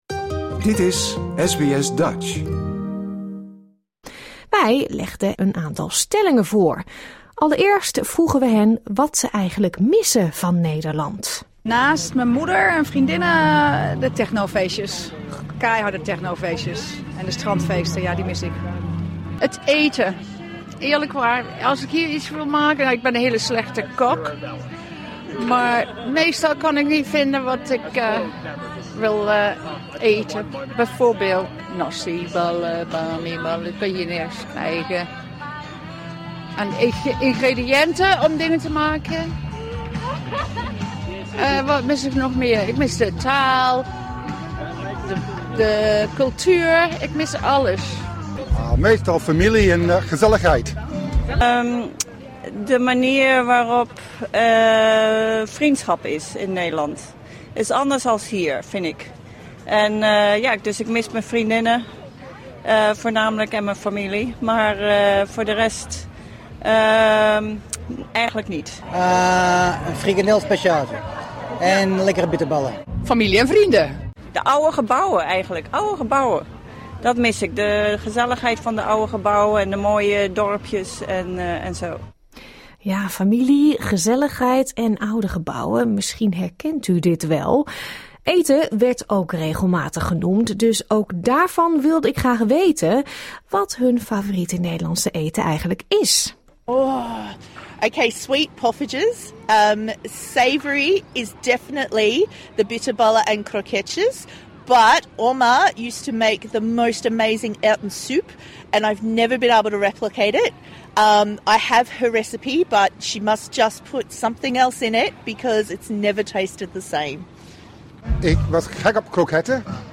In maart legden wij bezoekers van het Holland Festival enkele stellingen voor.